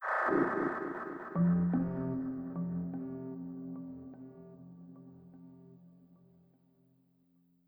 OS2 Warp 7.0 Startup.wav